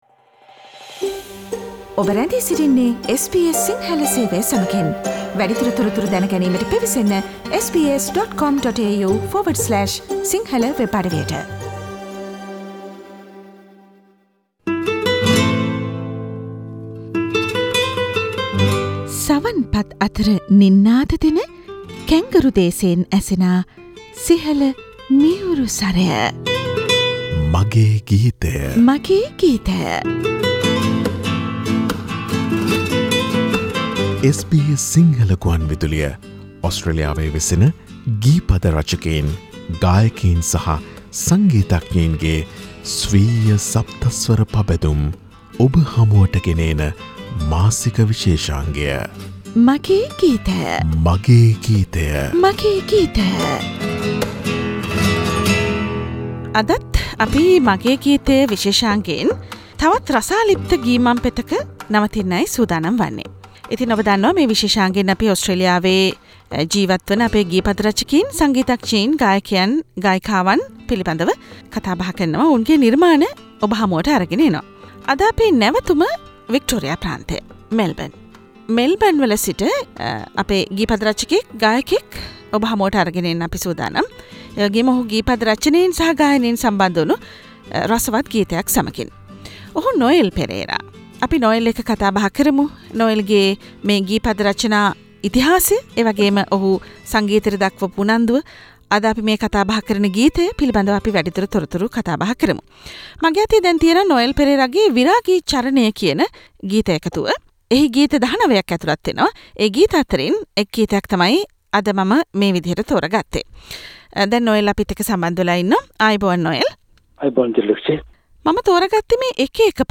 Melbourne based lyrist and a vocalist